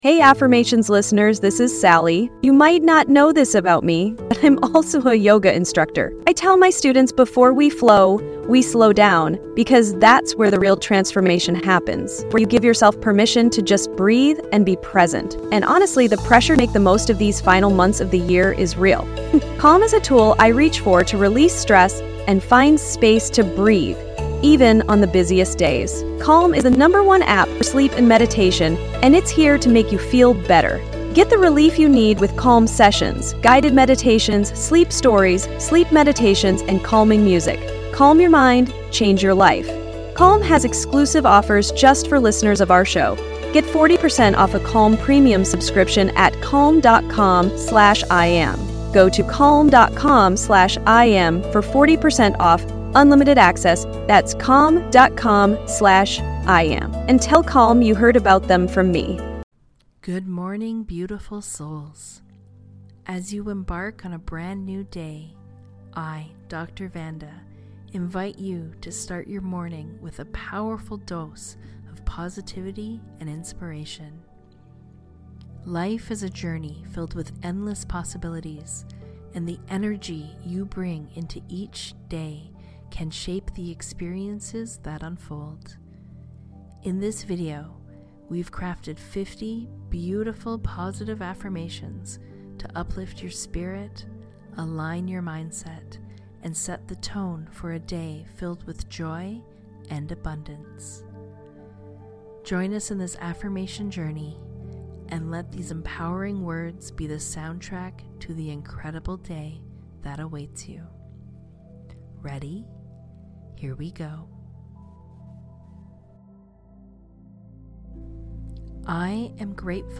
Positive Morning Affirmations ✨ Start Your Day the Right Way ✨ (affirmations said once)